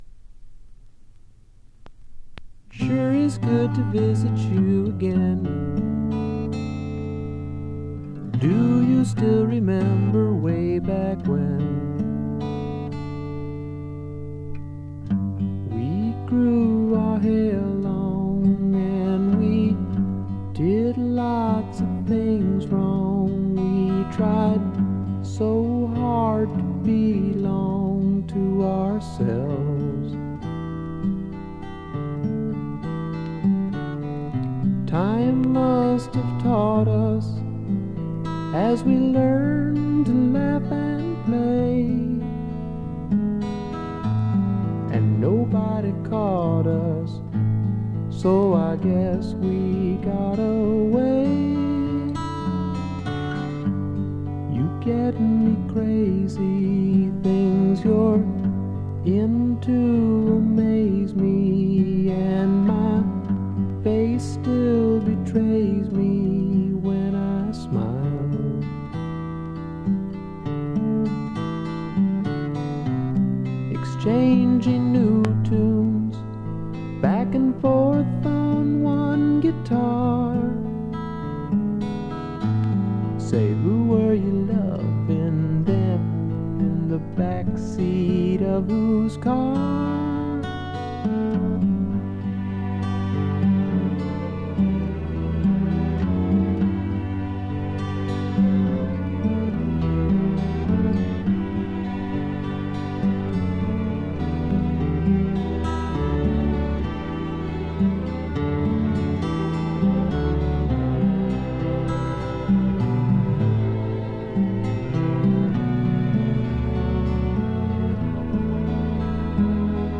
another gentle and gorgeous song.